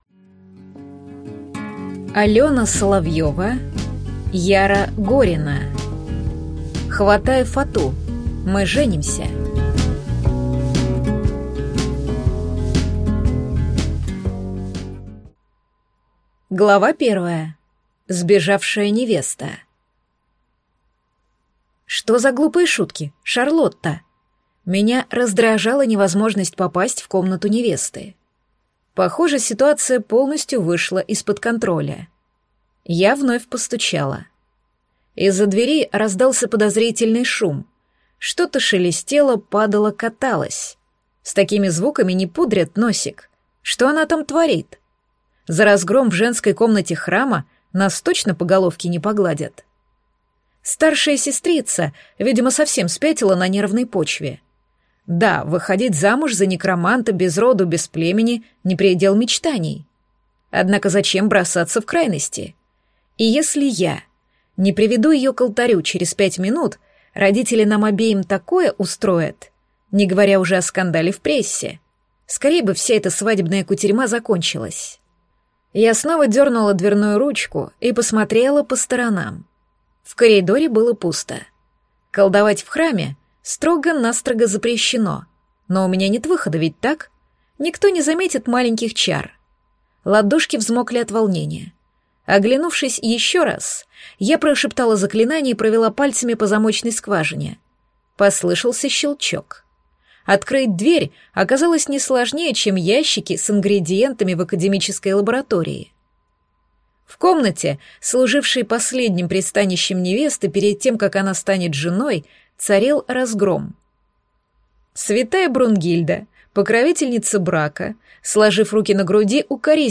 Аудиокнига Хватай фату! Мы женимся | Библиотека аудиокниг